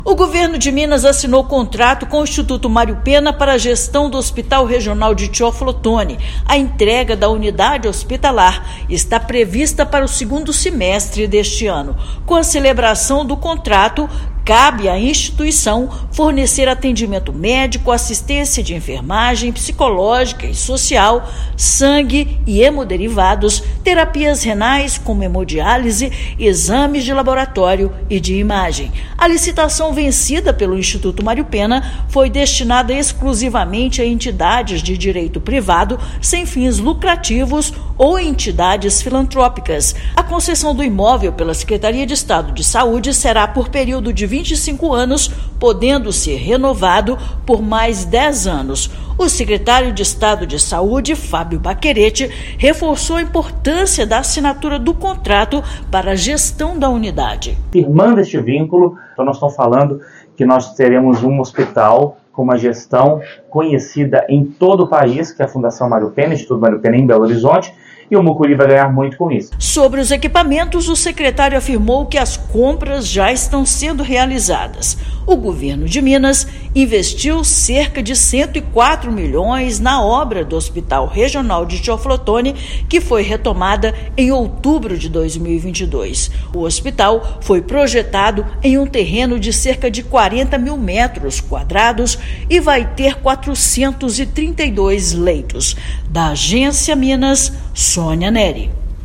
Até o momento, foram investidos cerca de R$ 104 milhões na obra que vai beneficiar 835.346 habitantes da região. Ouça matéria de rádio.